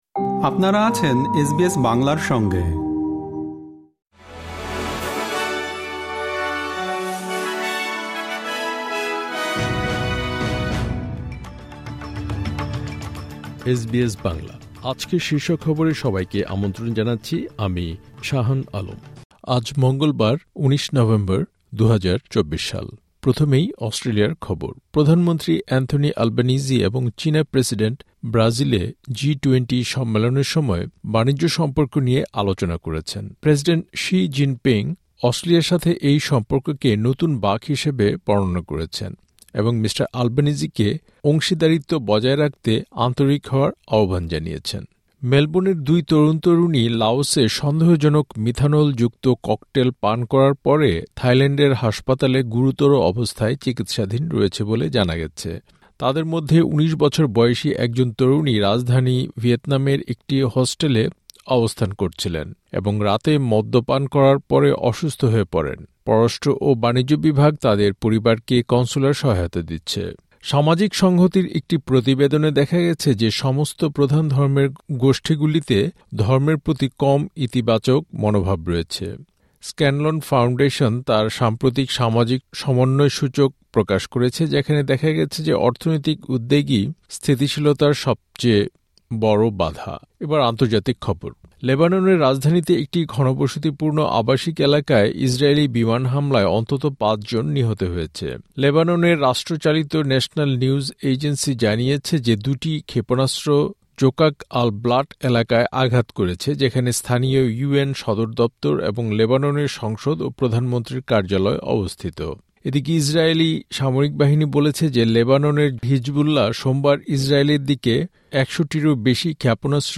এসবিএস বাংলা শীর্ষ খবর: ১৯ নভেম্বর, ২০২৪